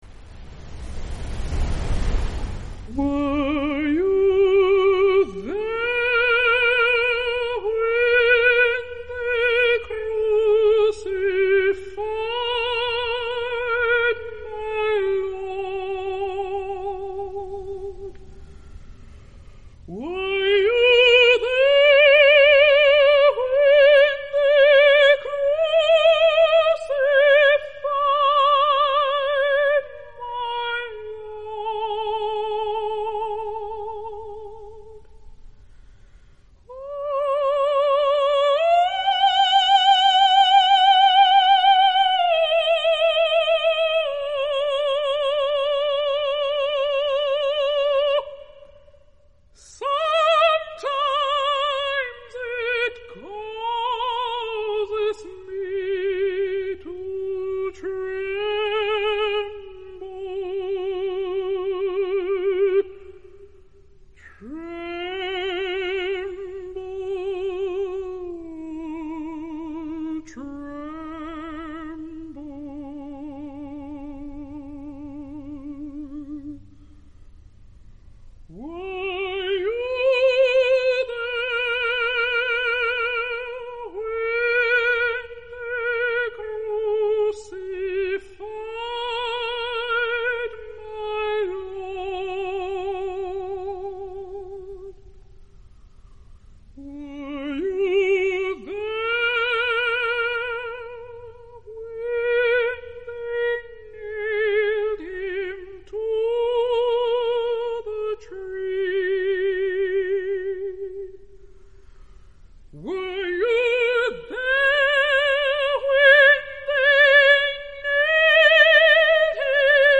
Anonymous African American Slave Spiritual